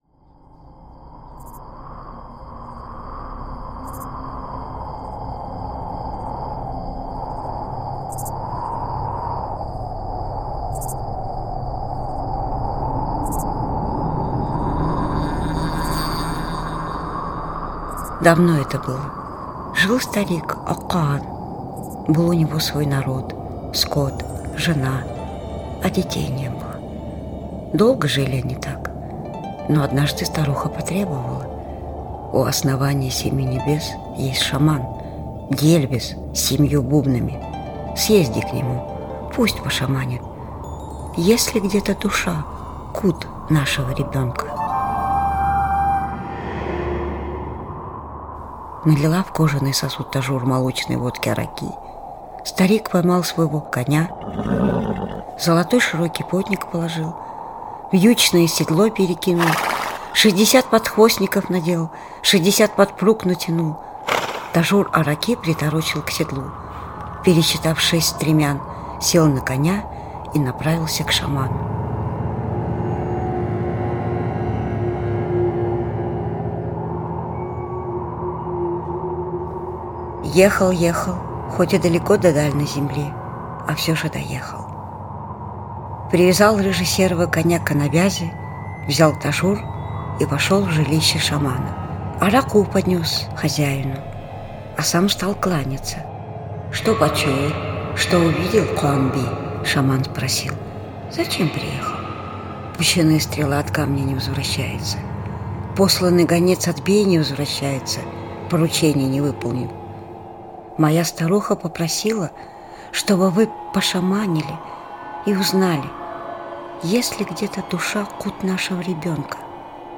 Мака-Маатыр и шестиглазая Карагыс - алтайская аудиосказка. Жил старик Ак-Каан, был у него свой народ, скот, жена, а детей не было...